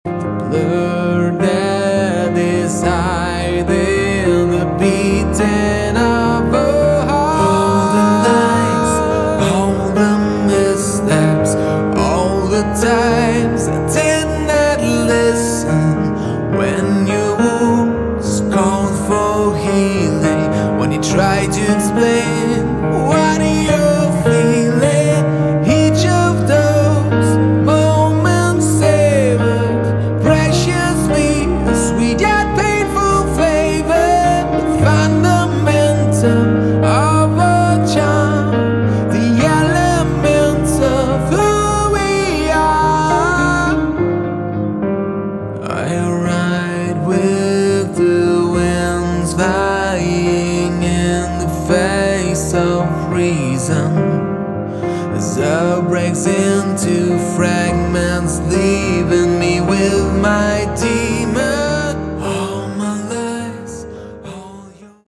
Category: Prog Rock/Metal
vocals, additional guitars
keyboards
guitars
bass
drums